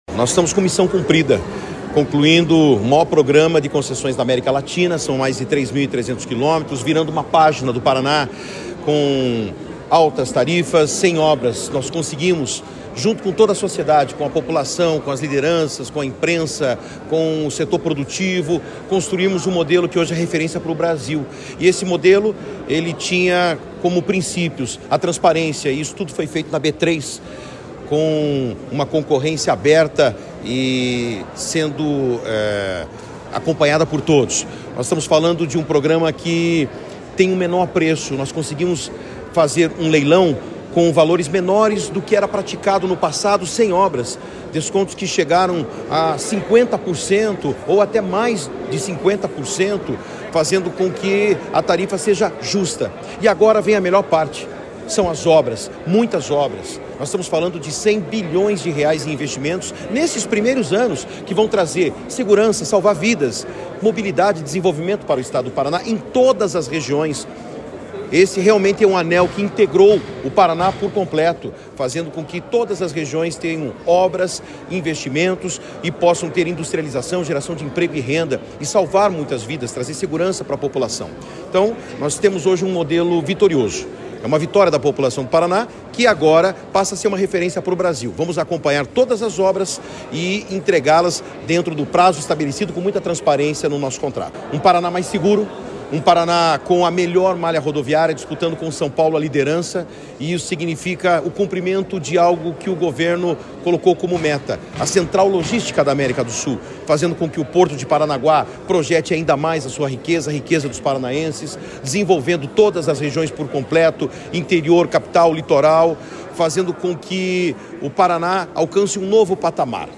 Sonora do secretário de Infraestrutura e Logística, Sandro Alex, sobre o resultado do leilão do Lote 5 das Rodovias Integradas do Paraná